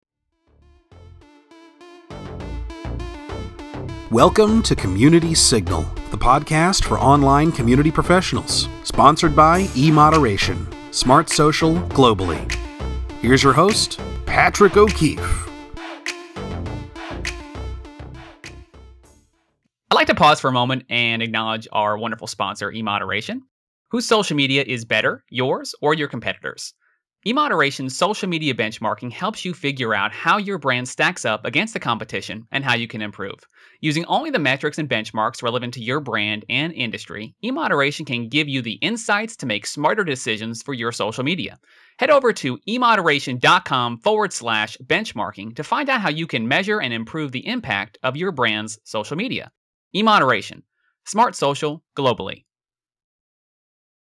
• A 30 second advertisement, read or ad-libbed by the host, during the show